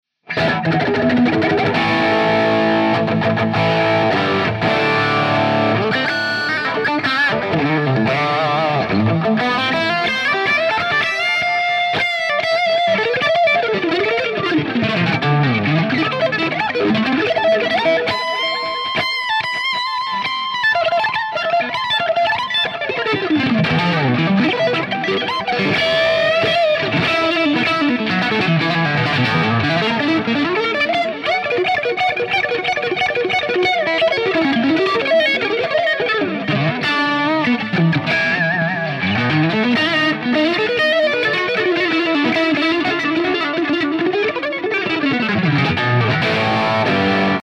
It's incredible sounding when pushed! Bright, edgy and alot of attack!
Improv
RAW AUDIO CLIPS ONLY, NO POST-PROCESSING EFFECTS